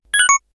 powerUp4.ogg